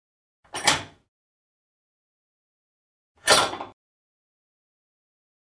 Звуки бурлящей воды
Звук затычка затыкает и извлекается из трубы ванна пробка раковина